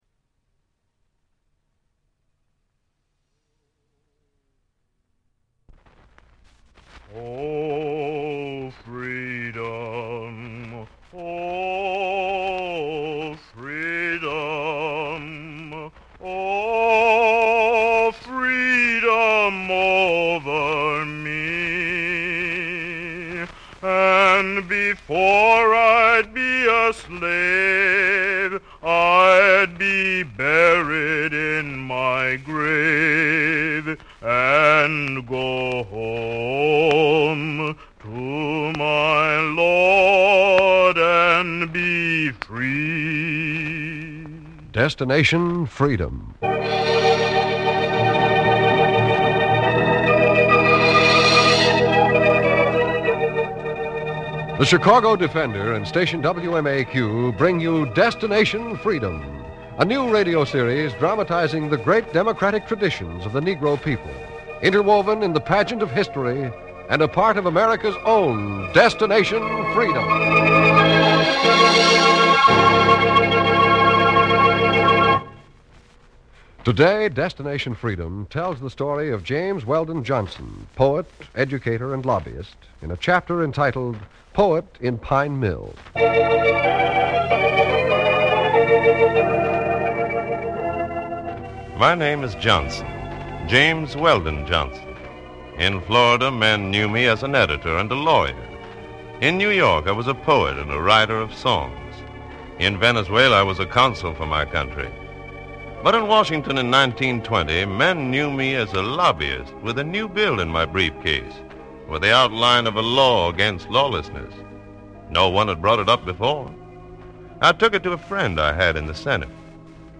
The show was instrumental in bringing to light the stories of African American leaders, artists, and activists, dramatizing their lives and contributions in a time when such representations were scarce in mainstream media.